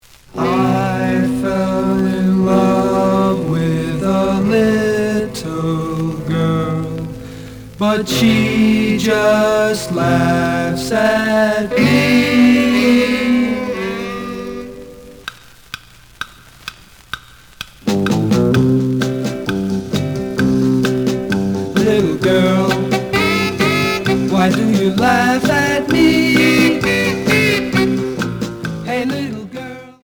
The audio sample is recorded from the actual item.
●Genre: Rhythm And Blues / Rock 'n' Roll
Slight noise on both sides.)